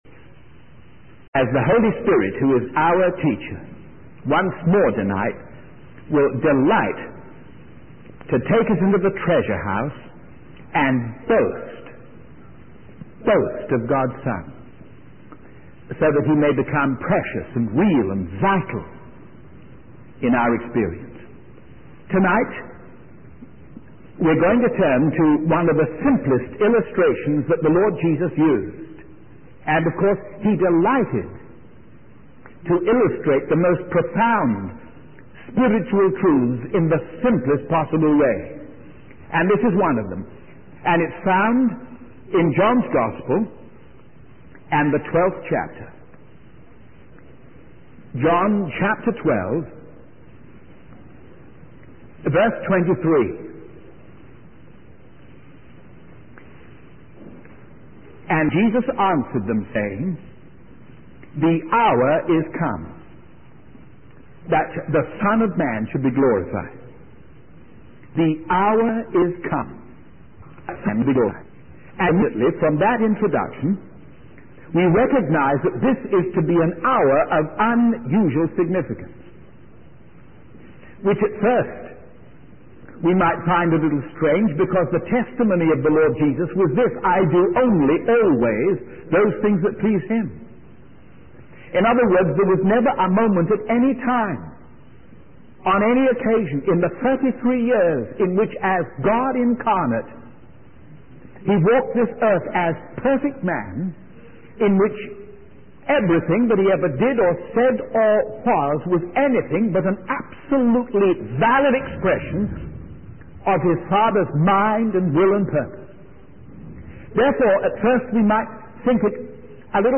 In this sermon, the preacher discusses the parable of the unfaithful servant who deceived his king and gained entry into a wedding feast without a wedding garment.